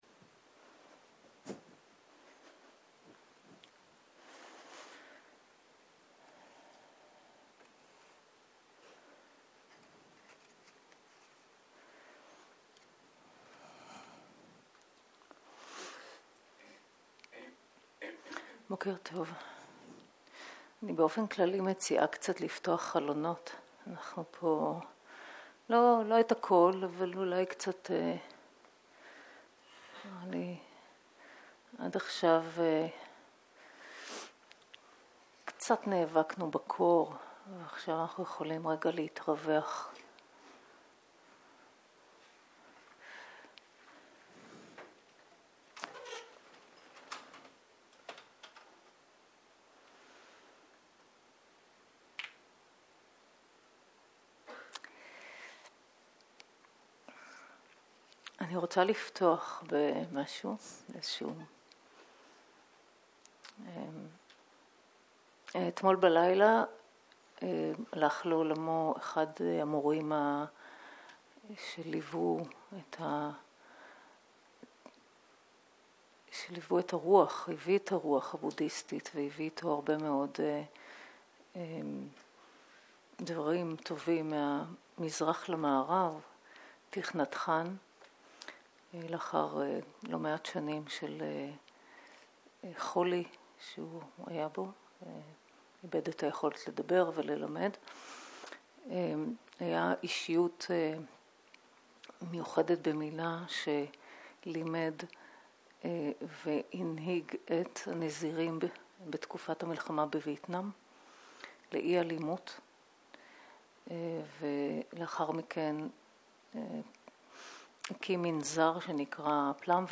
סוג ההקלטה: שיחת הנחיות למדיטציה